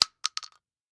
Bullet Shell Sounds
shotgun_generic_3.ogg